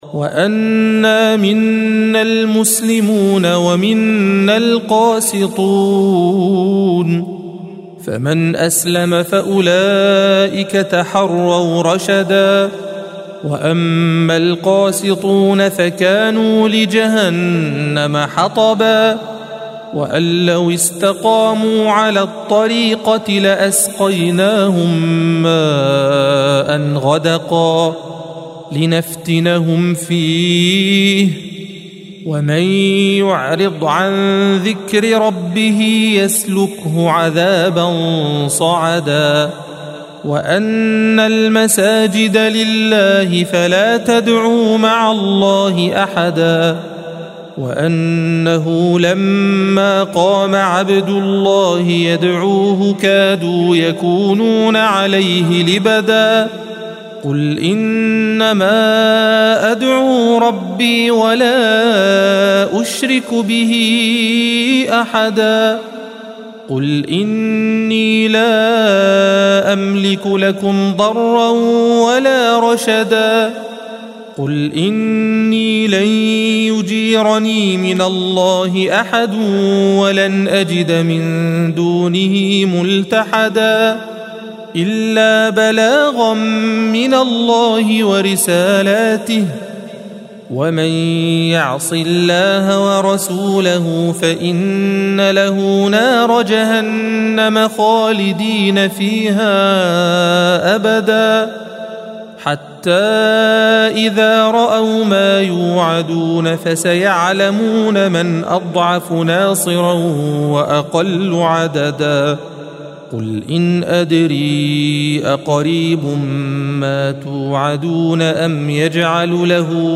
الصفحة 573 - القارئ